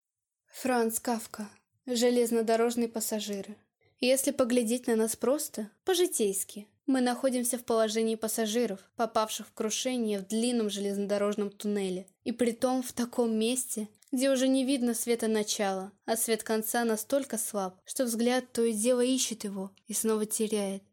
Аудиокнига Железнодорожные пассажиры | Библиотека аудиокниг